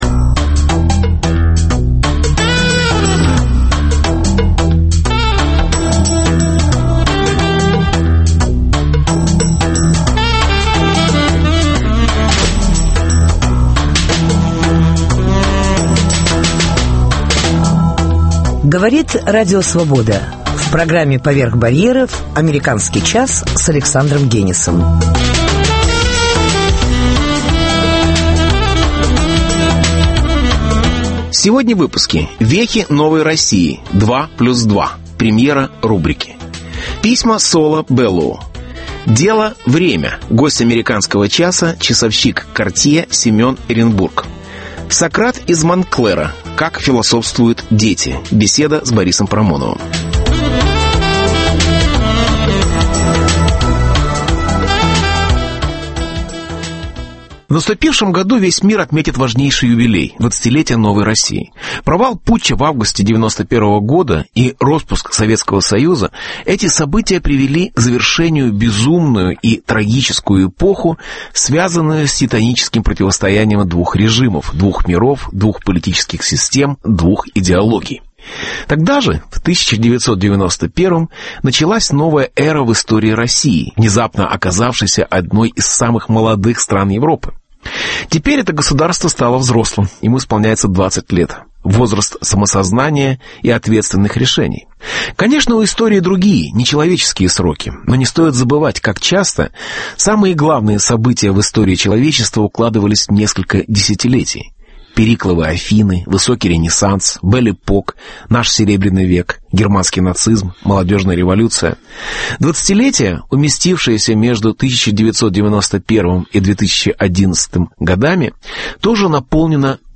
Сократ из Монтклэра: как философствуют дети. Беседа с Борисом Парамоновым.